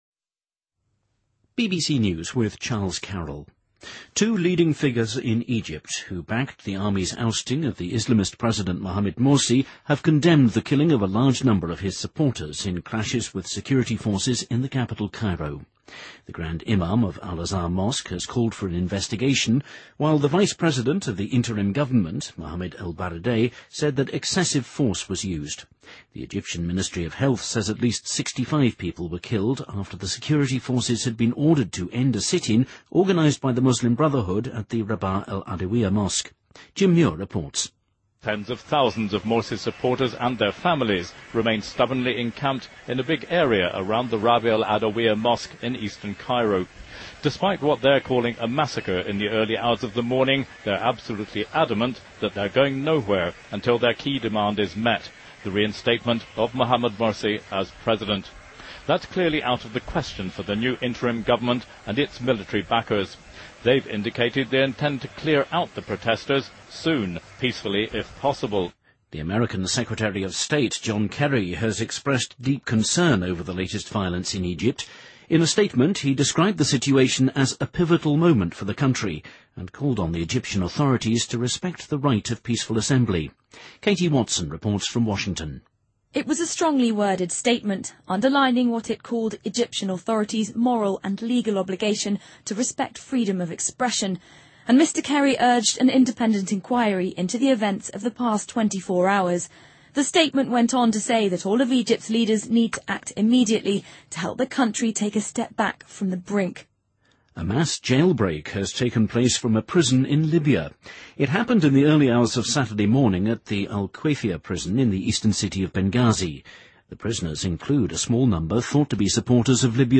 BBC news,利比亚一家监狱发生大规模越狱事件